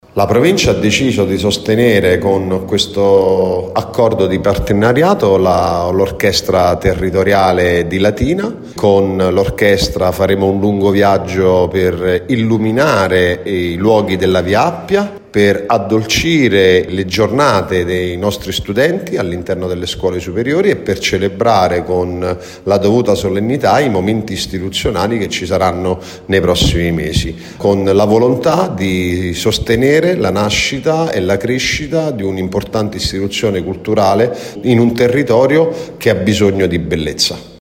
A credere sin da subito nel valore del progetto è stata la Provincia di Latina, che ha deciso di sostenere l’orchestra attraverso un partenariato istituzionale. Le motivazioni del sostegno sono state illustrate dal presidente Gerardo Stefanelli nel corso di una conferenza stampa tenutasi presso l’Auditorium del Conservatorio “Ottorino Respighi” di Latina: